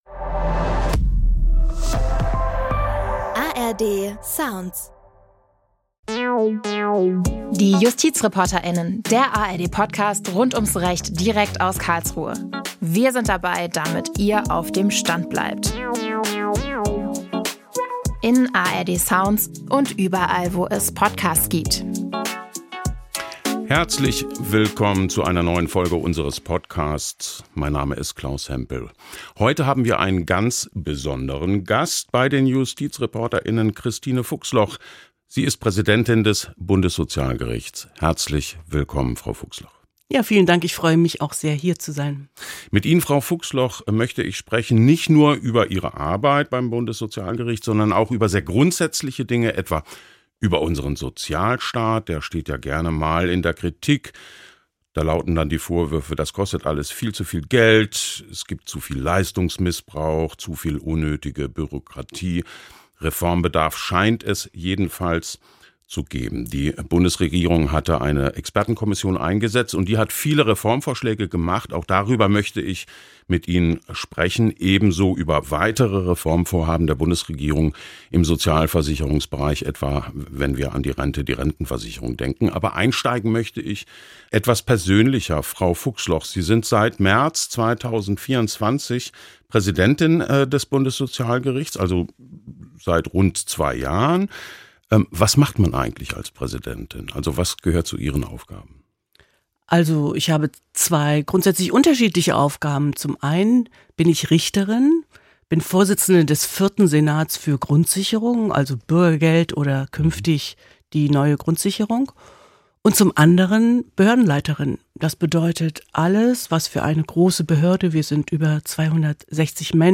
praesidentin-des-bundessozialgerichts-christine-fuchsloch-im-gespraech.b79.mp3